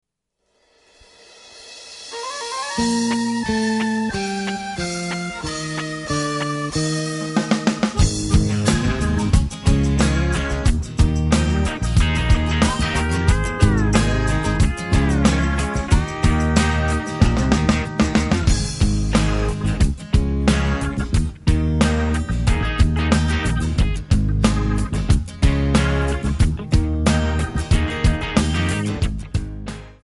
C/Db
Backing track Karaoke
Country, 2000s